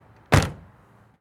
car door go shut